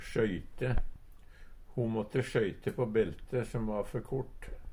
DIALEKTORD PÅ NORMERT NORSK sjøyte skøyte Infinitiv Presens Preteritum Perfektum sjøyte sjøyta sjøyta sjøyta Eksempel på bruk Ho måtte sjøyte på belte som va før kort.